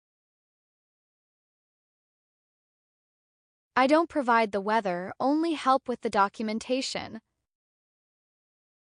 alternate VO plagiarized from the plagiarism machine